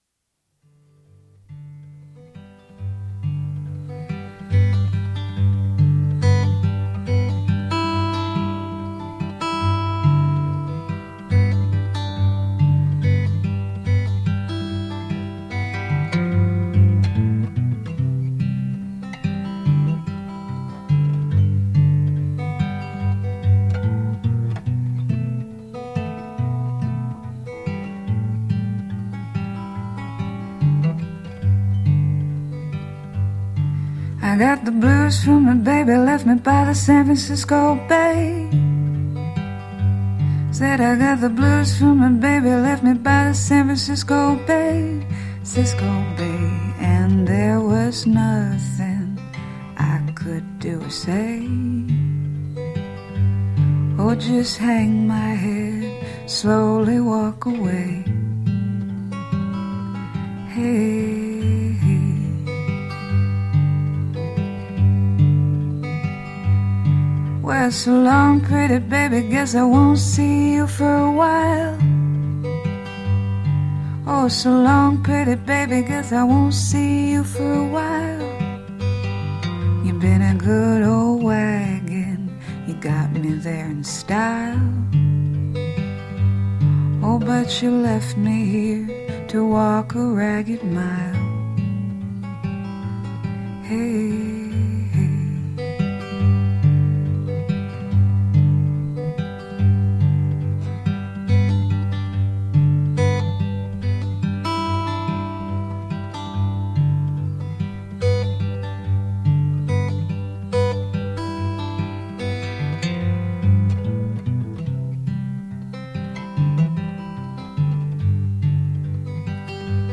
Just guitar and voice
It was a little spooky.